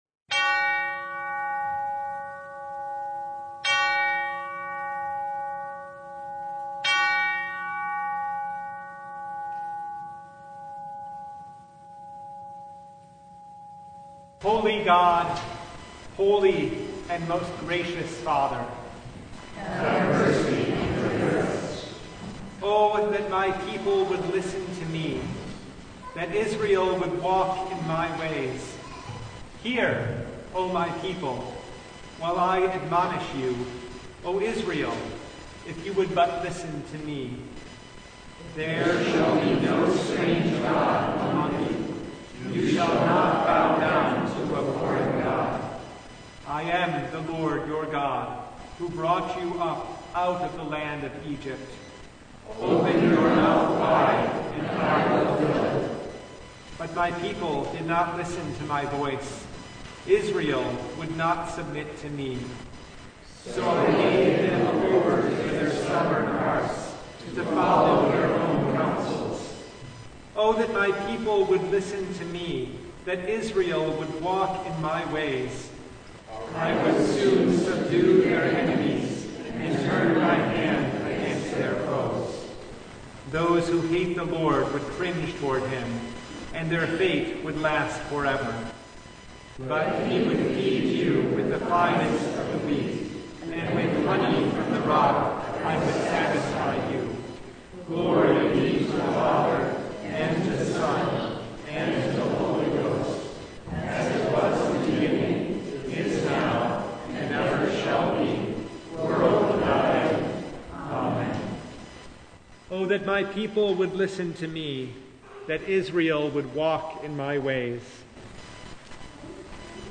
Service Type: Lent Midweek Noon